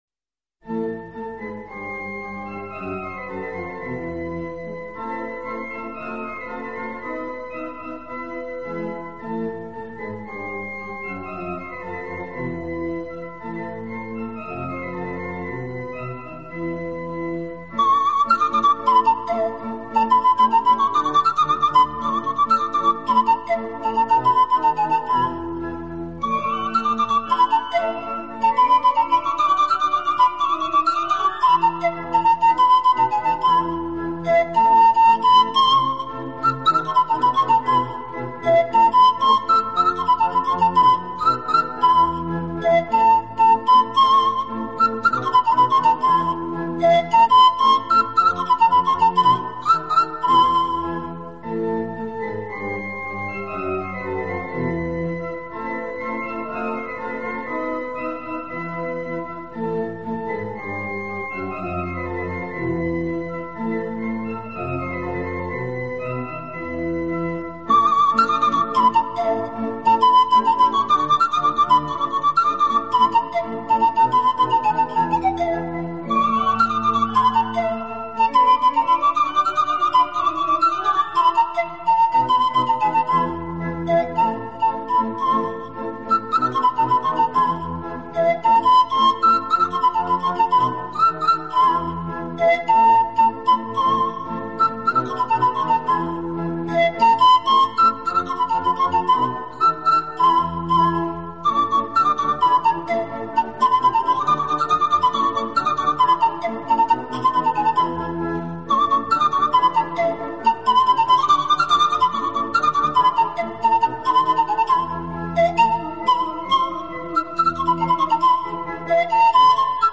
置身于世外桃园,尽情享受这天簌之音……